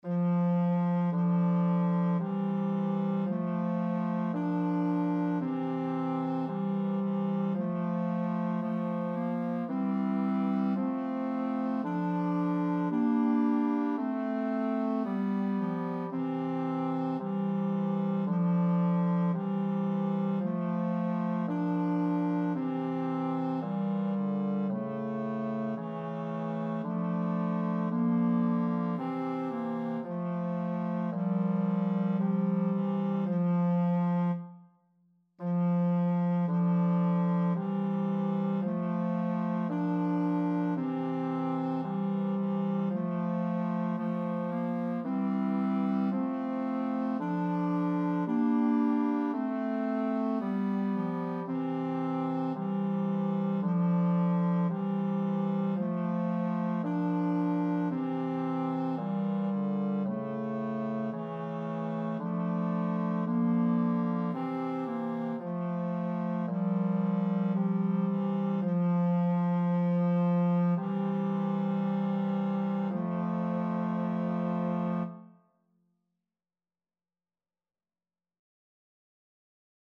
Number of voices: 3vv Voicing: SSA Genre: Sacred, Hymn
Language: Latin Instruments: A cappella